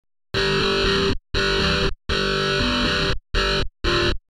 オーディオ・デモ（自作です）
Distortion
価格からは信じられないくらい、リアルな音色です。
dist.mp3